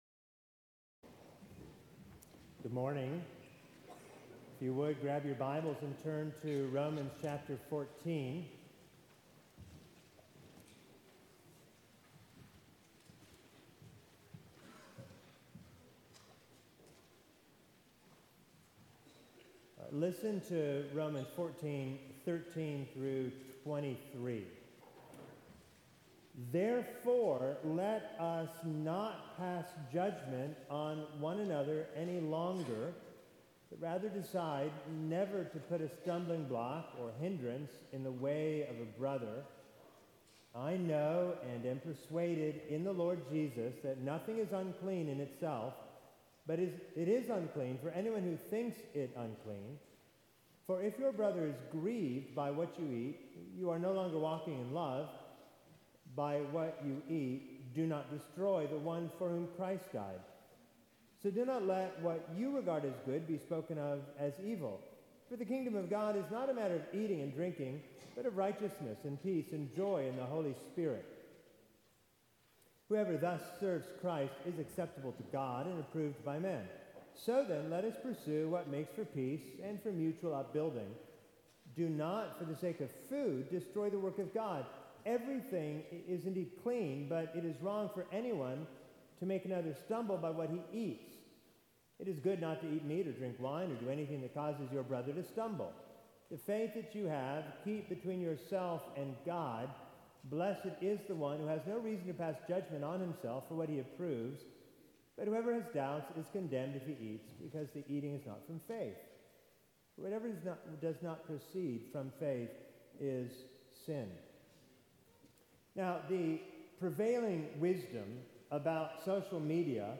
preaches on “How Do We Pursue Peace?” from Romans 14:13–23.